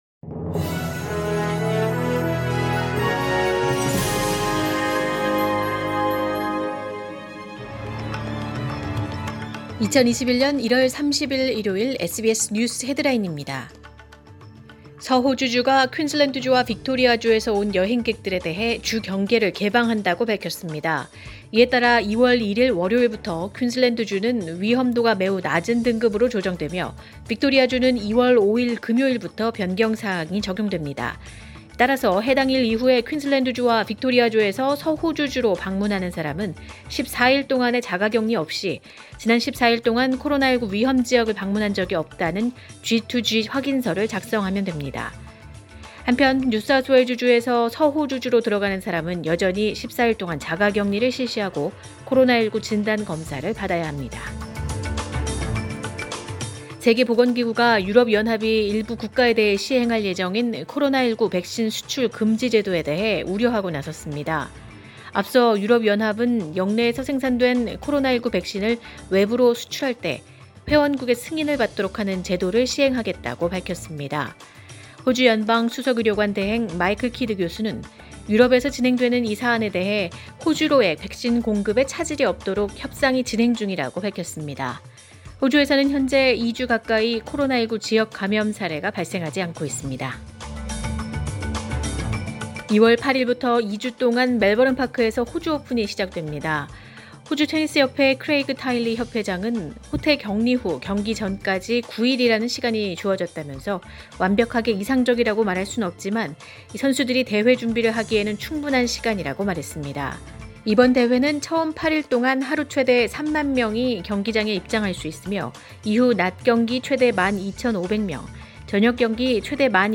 2021년 1월 31일 일요일 SBS 뉴스 헤드라인입니다.